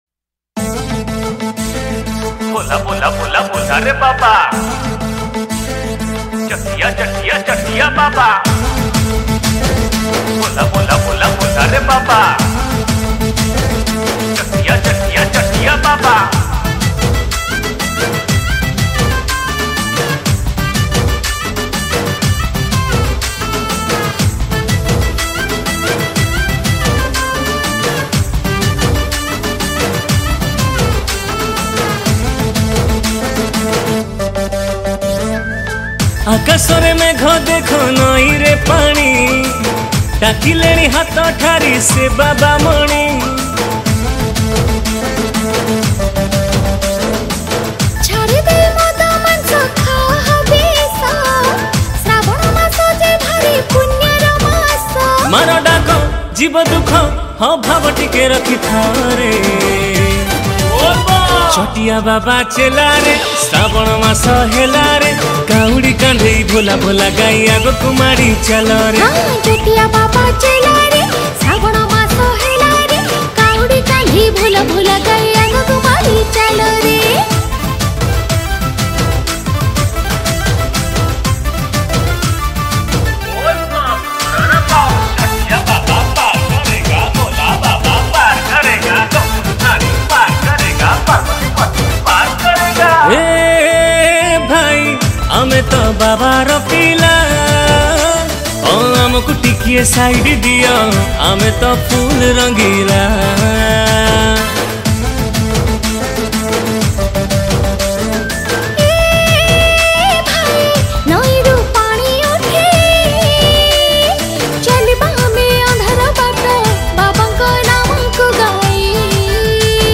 Bolbum Special Song Songs Download